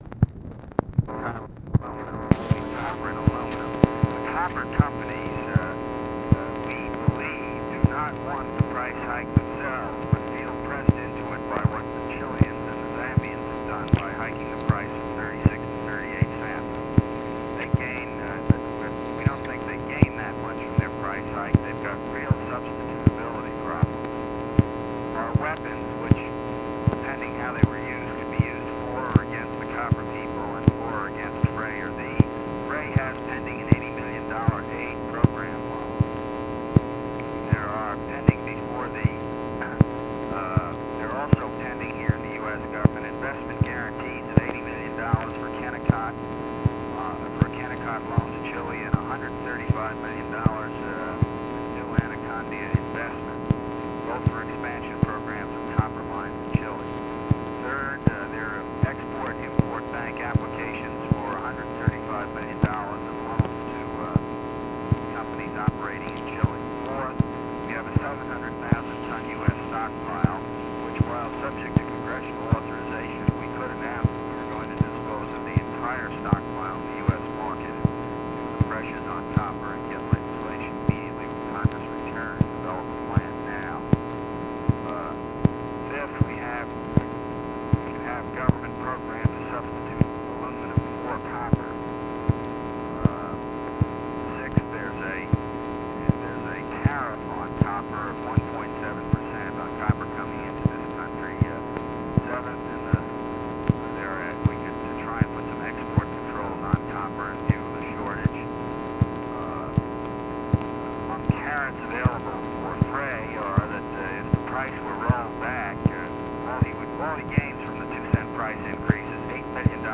Conversation with JOSEPH CALIFANO and ROBERT MCNAMARA, November 13, 1965
Secret White House Tapes